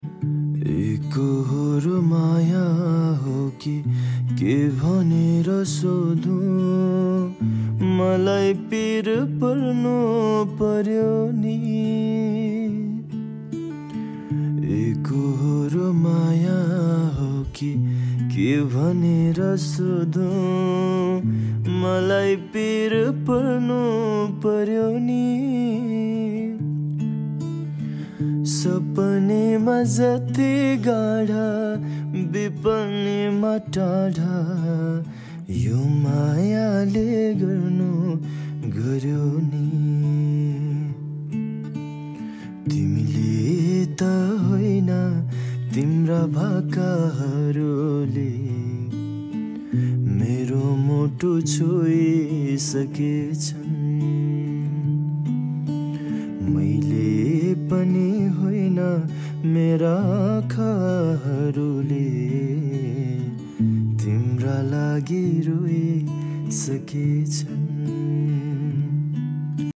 raw cover